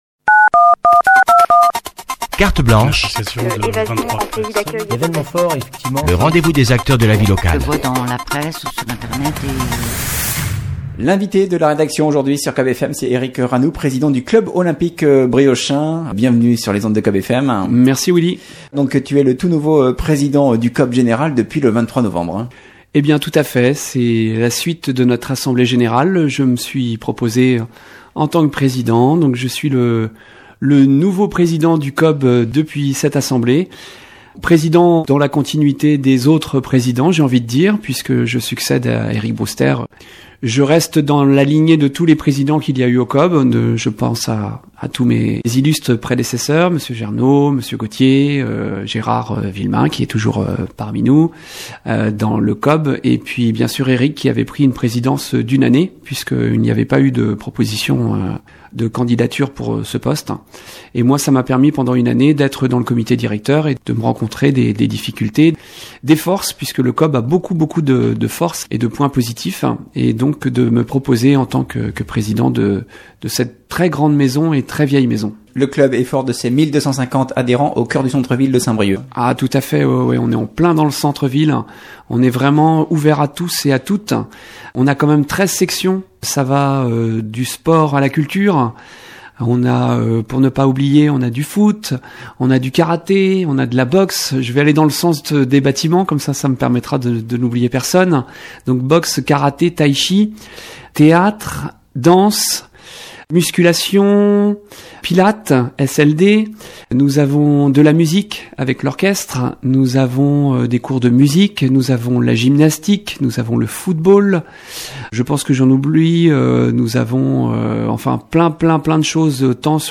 Invité de la rédaction aujourd’hui